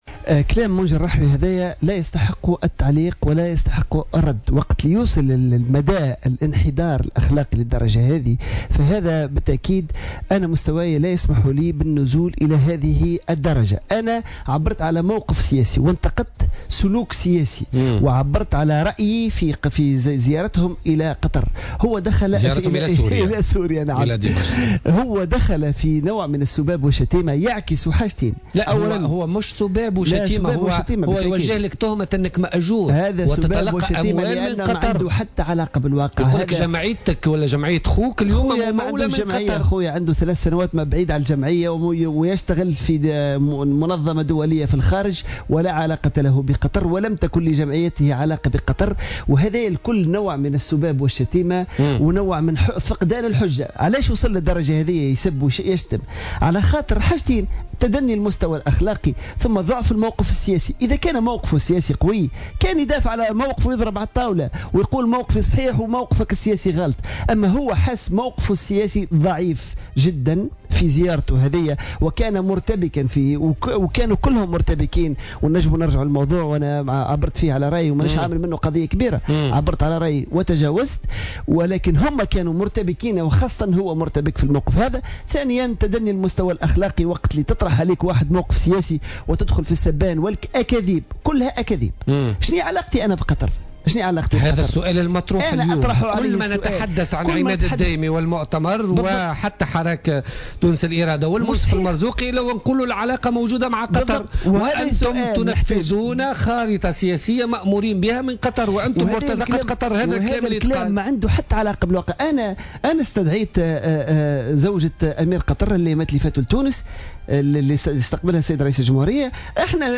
وأوضح الدايمي في مداخلة له اليوم في برنامج "بوليتيكا" أنه لم يكن لجمعية شقيقه أي علاقة بقطر وأنه يعمل في منظمة دولية في الخارج، مشيرا إلى أن مثل هذه التهم تعكس ضعف الموقف السياسي للرحوي.